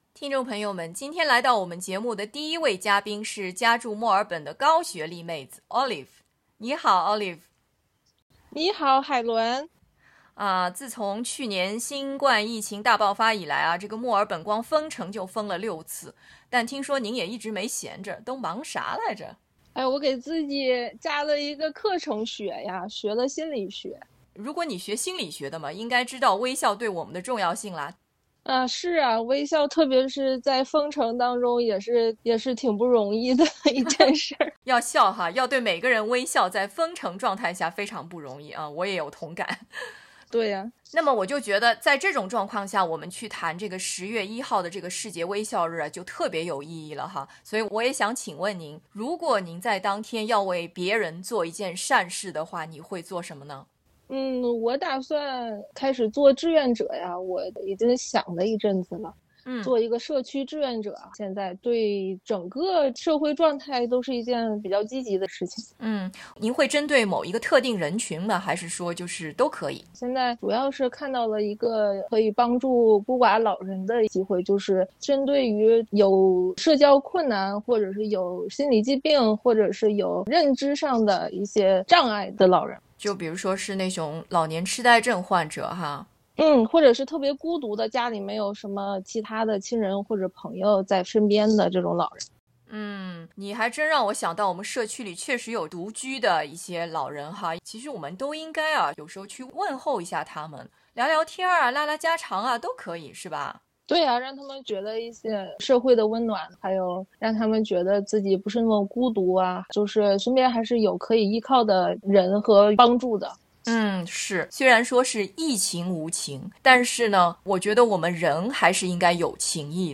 為了慶祝世界微笑日，四位受訪者談了他們將如何在那天把愛心送給他人。
smile_day_interviewing_4_people.mp3